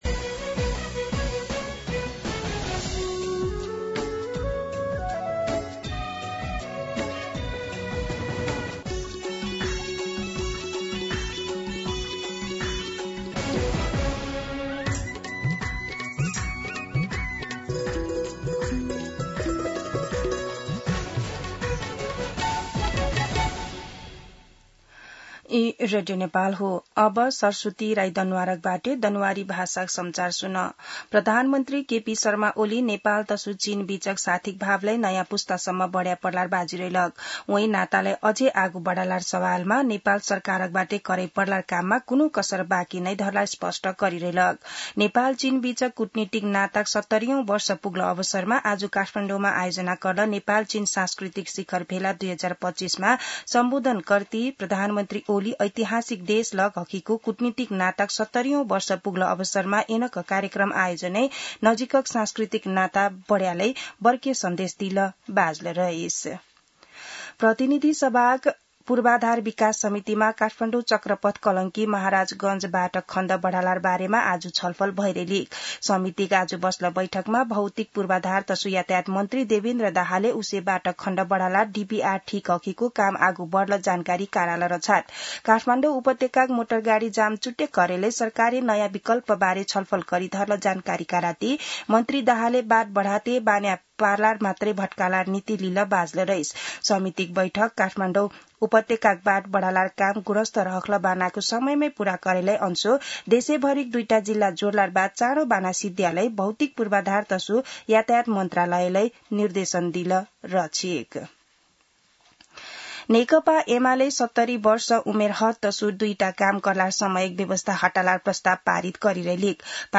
An online outlet of Nepal's national radio broadcaster
दनुवार भाषामा समाचार : २ साउन , २०८२